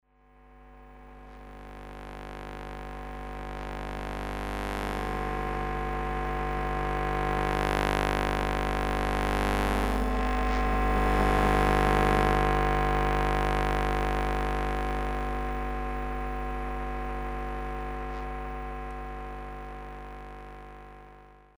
In order to acoustically illustrate the sounds of public space that cannot be heard by human ears but can be registered with help of special equipment, for the beginning as a short introduction to the topic, following sounds were recorded in Ljubljana:
Small street lamp in Ljubljana
smal_street_lamp_ljubljana.mp3